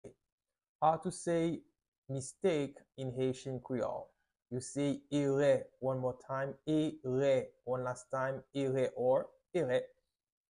How to say "Mistake" in Haitian Creole - "Erè" pronunciation by a native Haitian teacher
“Erè” Pronunciation in Haitian Creole by a native Haitian can be heard in the audio here or in the video below:
How-to-say-Mistake-in-Haitian-Creole-Ere-pronunciation-by-a-native-Haitian-teacher.mp3